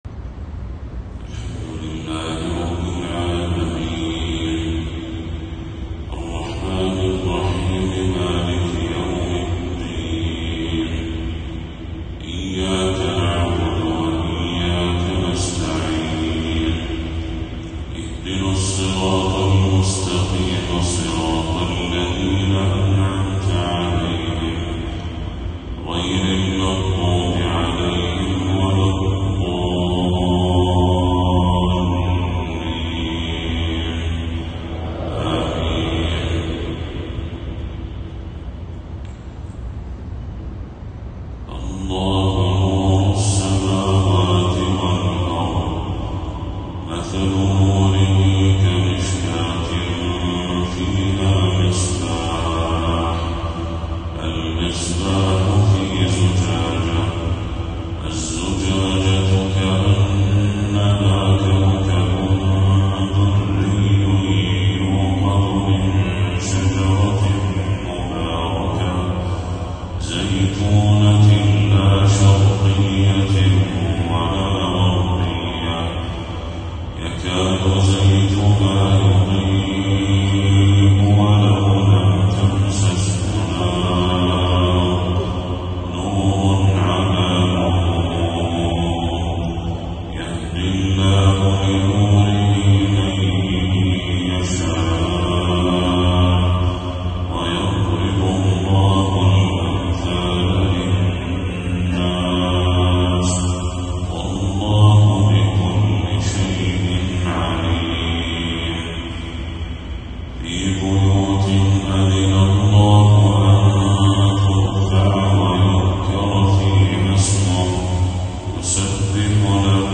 تلاوة ندية من سورة النور للشيخ بدر التركي | فجر 16 صفر 1446هـ > 1446هـ > تلاوات الشيخ بدر التركي > المزيد - تلاوات الحرمين